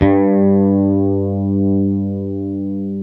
Index of /90_sSampleCDs/Roland LCDP02 Guitar and Bass/BS _Jazz Bass/BS _Jazz Basses